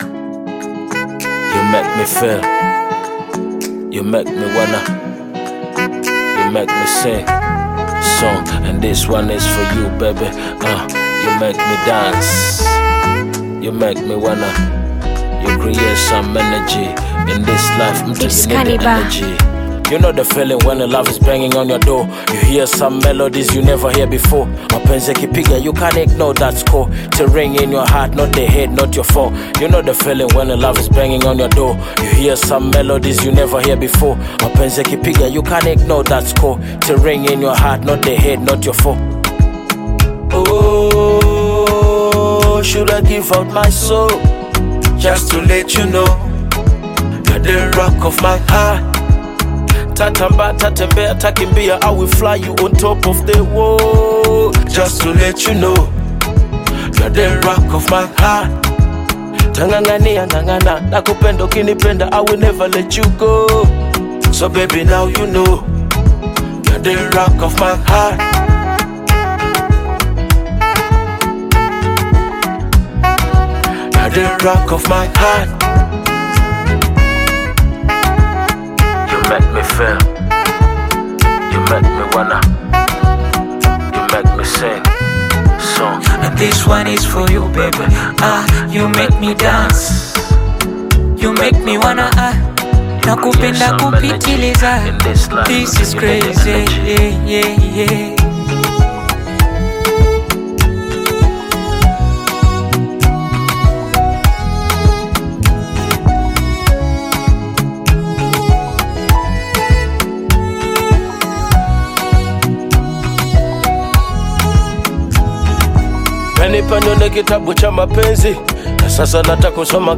This catchy new song
Bongo Flava You may also like